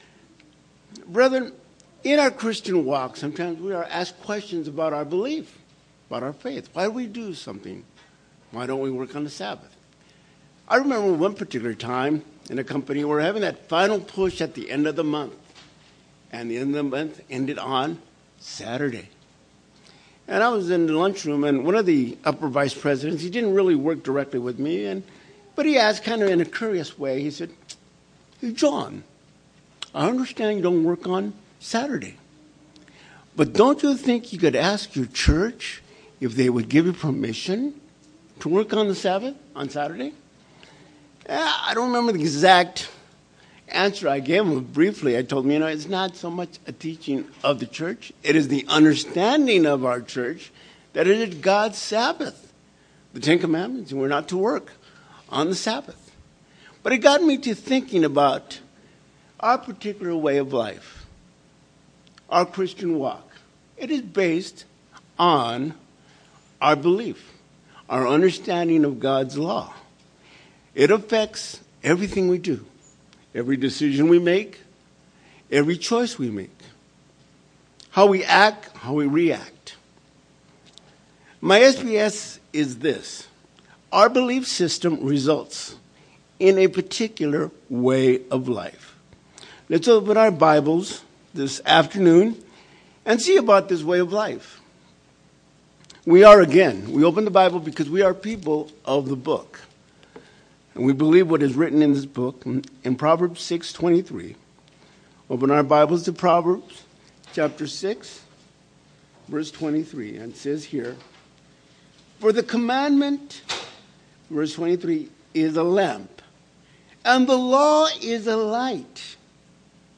Given in Redlands, CA